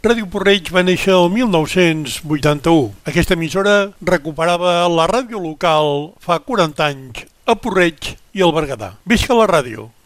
Emès amb motiu del Dia mundial de la ràdio 2022.